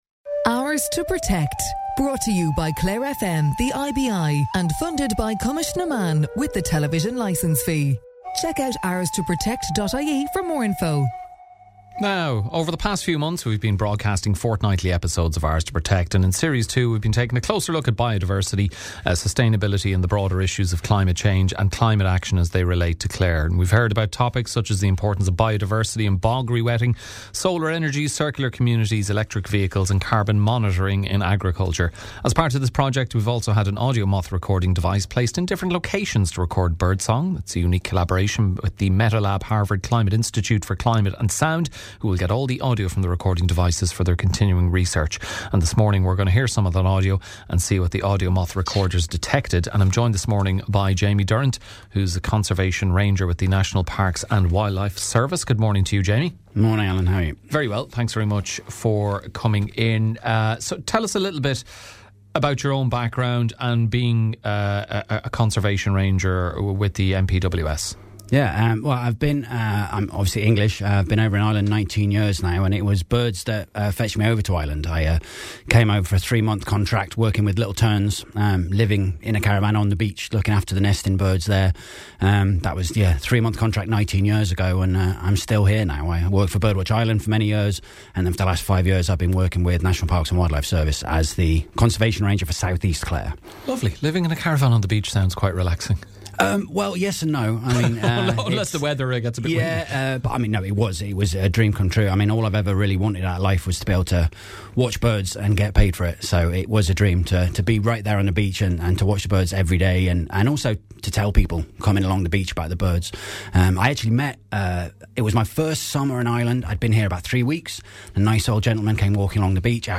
This morning we’re going to hear some of that audio and see what the AudioMoth recorders detected.